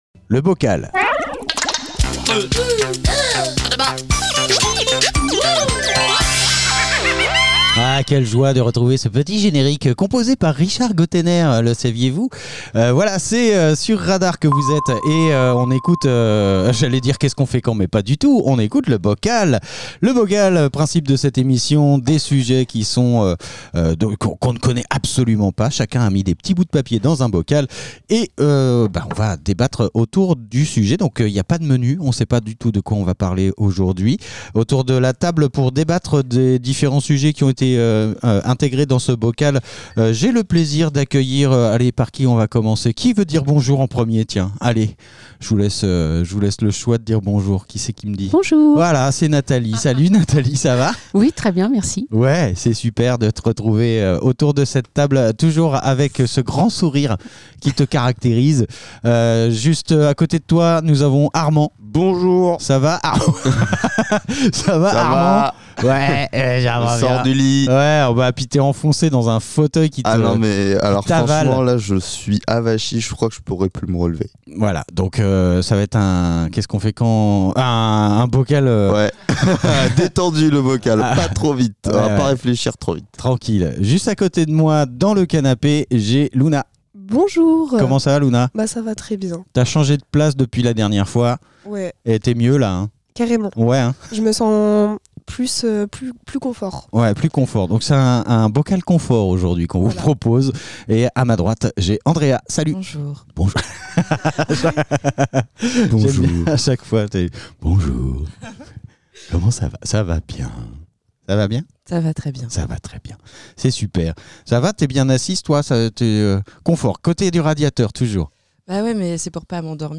Une poignée d'invités propose des sujets de débats à bulletin secret. Ces petits papiers sont délicatement mélangés pour n'en tirer qu'un seul au sort. Le sujet, une fois dévoilé, donne lieu à des conversations parfois profondes, parfois légères, toujours dans la bonne humeur !